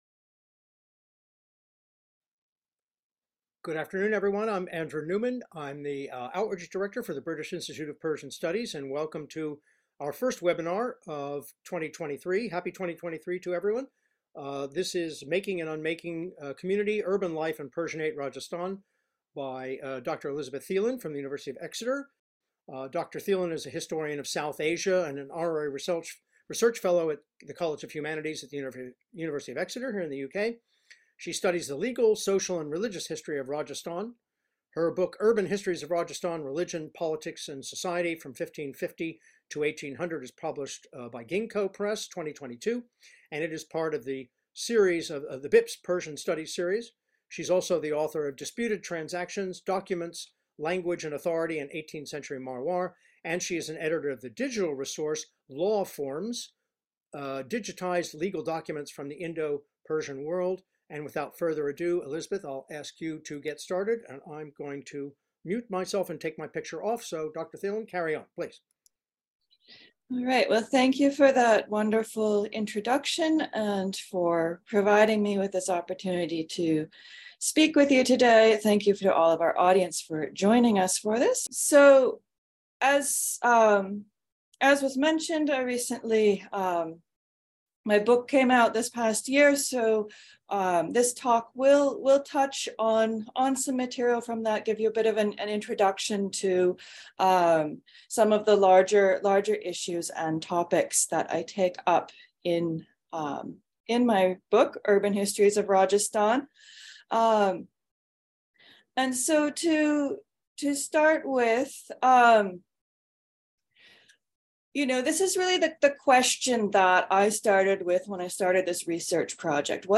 How did diverse religious, occupational, and ethnic communities live side-by-side in precolonial Rajasthan’s cities? This talk presents several case studies from the city of Nagaur that show how people ranging from Sufi Shaikhs to cloth-dyers, and kings to cowherds understood and navigated social difference in the seventeenth and eighteenth centuries.